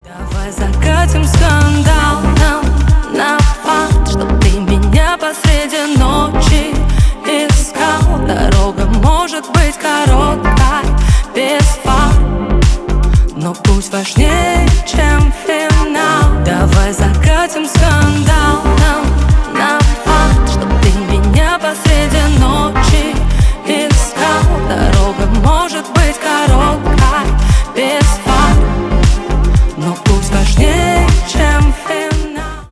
• Качество: 320, Stereo
поп
женский вокал
лирика